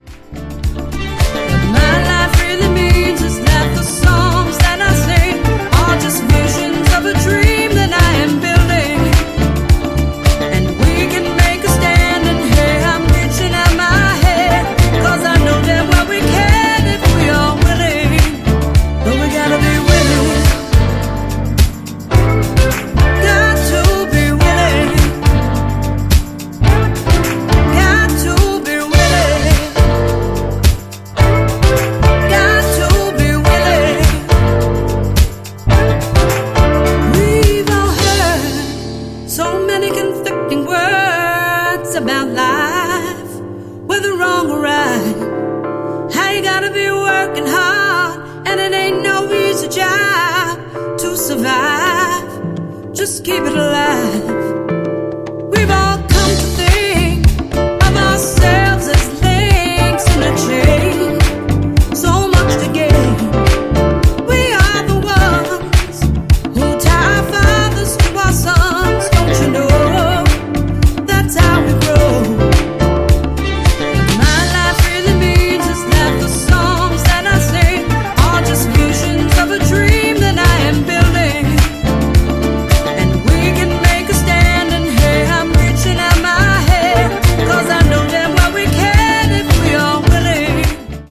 ジャンル(スタイル) HOUSE / DISCO / SOULFUL